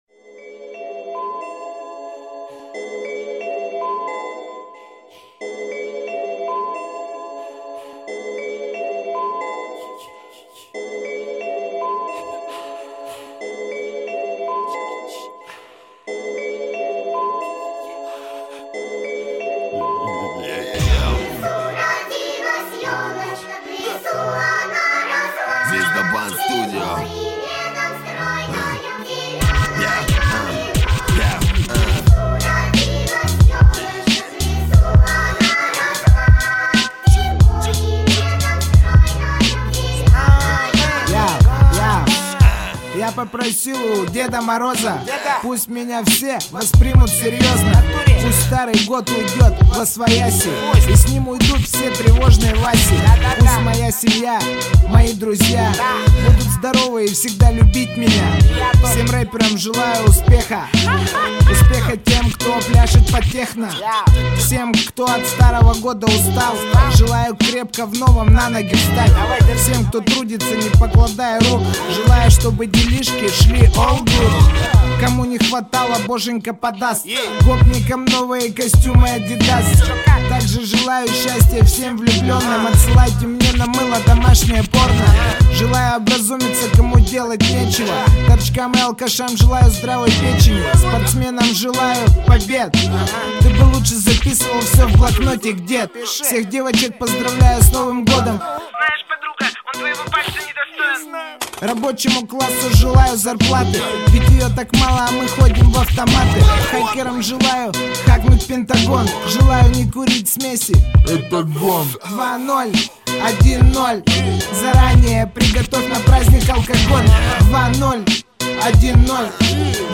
Главная » Статьи » Рэп